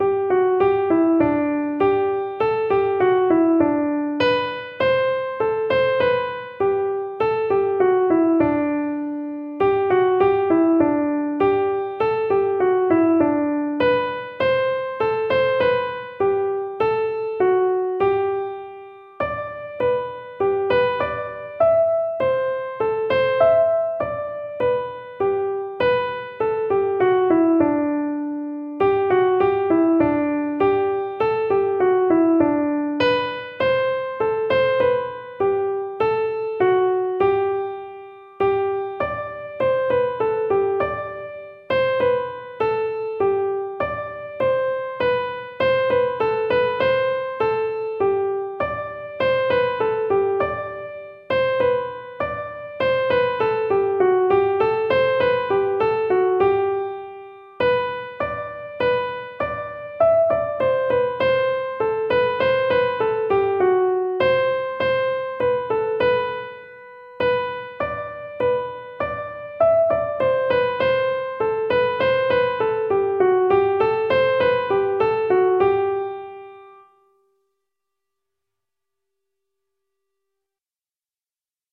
Each tune is available as a PDF (sheet music) and MP3 (audio recording played slowly for learning).